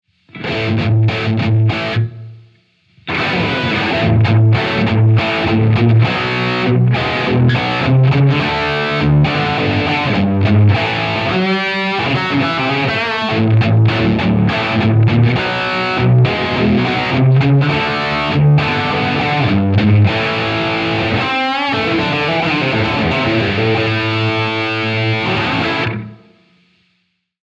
Zero feedback and older tape combine to create a short room-like ambience.